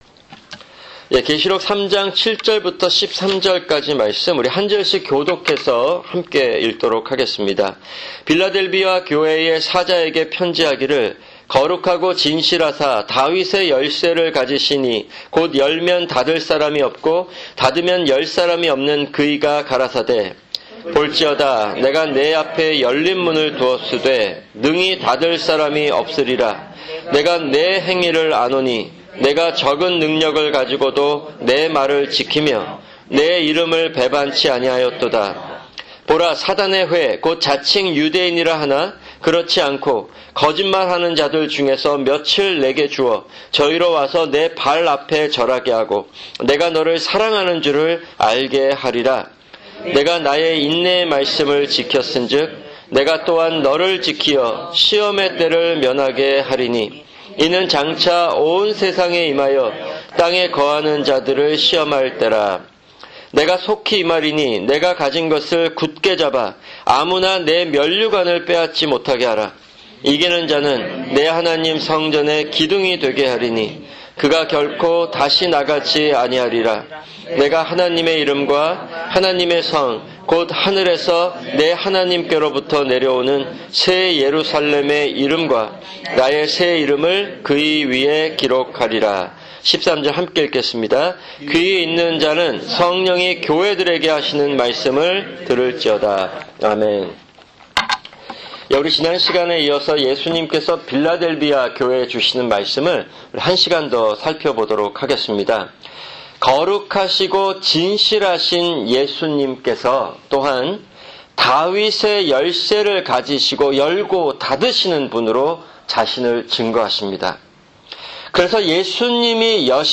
[주일 성경공부] 성경개관- 전도서(7)